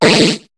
Cri de Barpau dans Pokémon HOME.